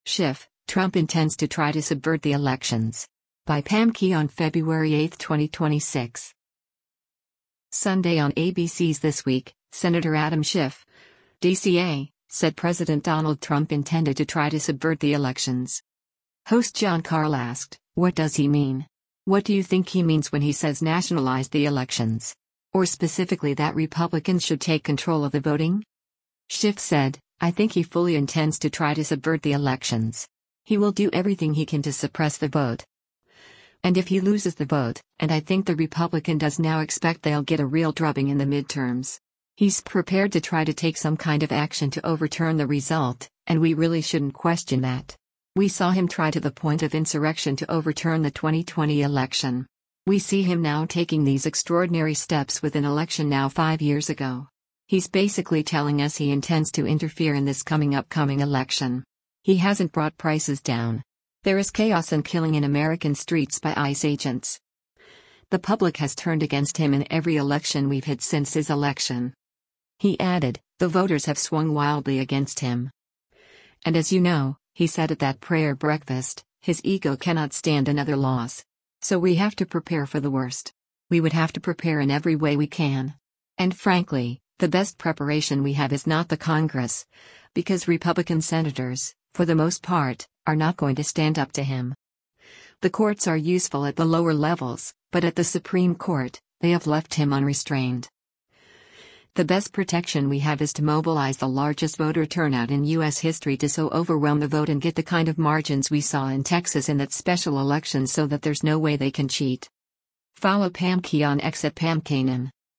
Sunday on ABC’s “This Week,” Sen. Adam Schiff (D-CA) said President Donald Trump intended “to try to subvert the elections.”
Host Jon Karl asked, “What does he mean? What do you think he means when he says nationalize the elections? Or specifically that Republicans should take control of the voting?”